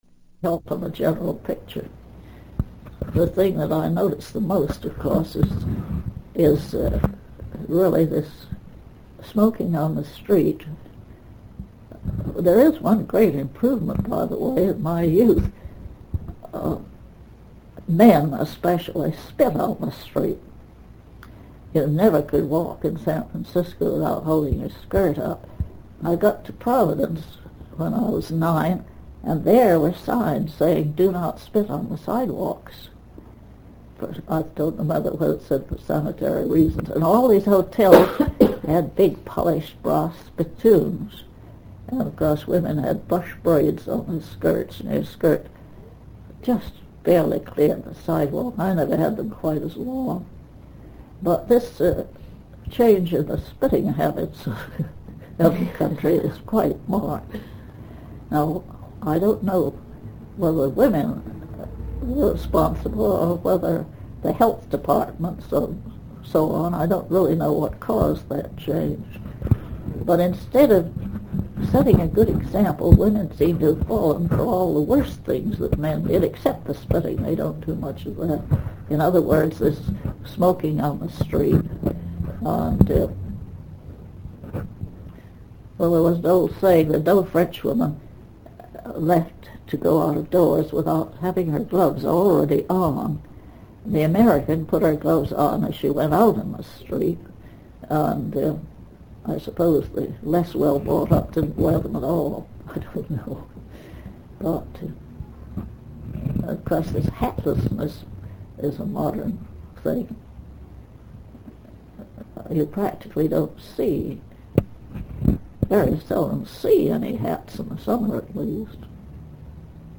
INTERVIEW DESCRIPTION
No introduction. Tape begins abruptly